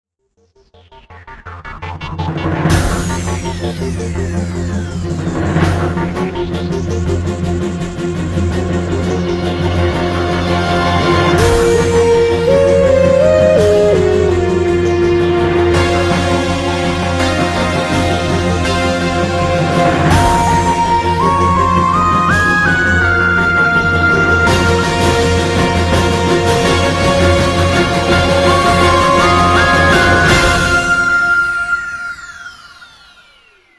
TV Theme